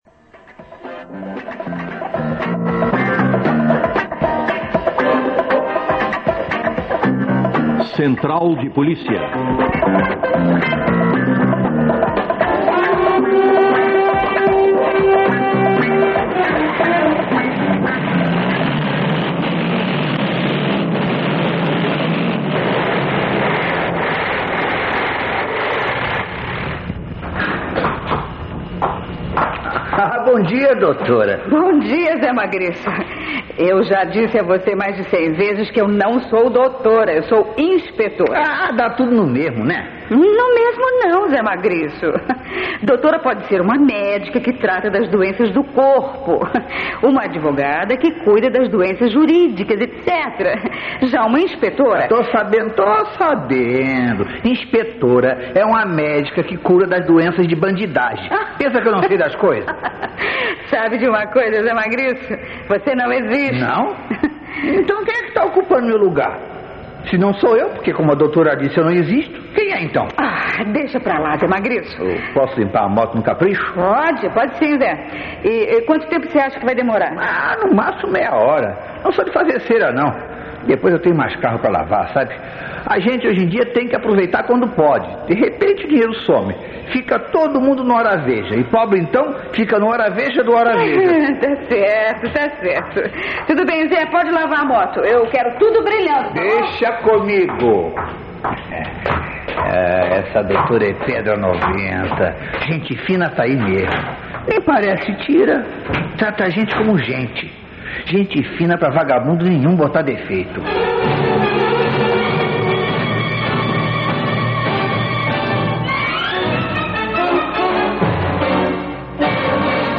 Radioteatro.. Central de Polícia
A qualidade de som está excelente.
É HORA DE "CENTRAL DE POLÍCIA" Radioteatro produzido na segunda metade dos anos 80 pela Rádio Nacional do RJ.. Numa espécie de "Revival" dos áureos tempos das dramatizações radiofônicas.